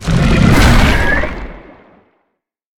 Sfx_creature_shadowleviathan_attack_01.ogg